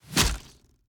Bow Attacks Hits and Blocks
Bow Impact Hit 2.ogg